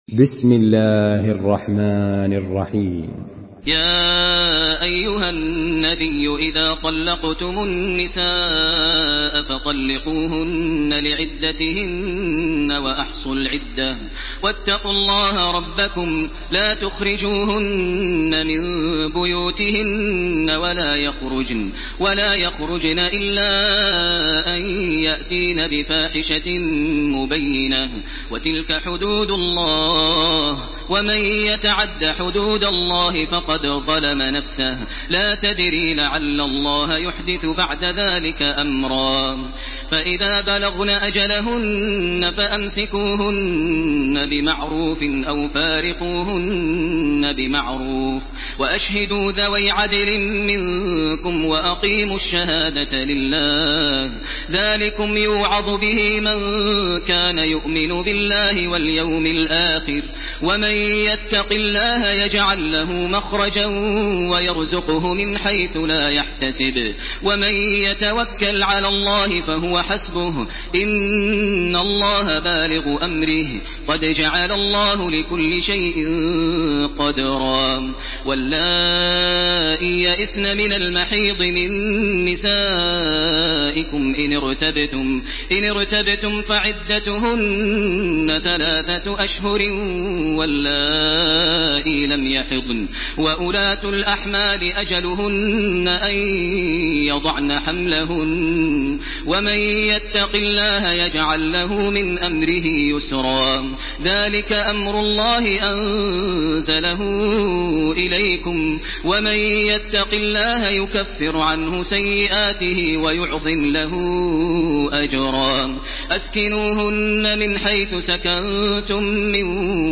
سورة الطلاق MP3 بصوت تراويح الحرم المكي 1429 برواية حفص عن عاصم، استمع وحمّل التلاوة كاملة بصيغة MP3 عبر روابط مباشرة وسريعة على الجوال، مع إمكانية التحميل بجودات متعددة.
تحميل سورة الطلاق بصوت تراويح الحرم المكي 1429
مرتل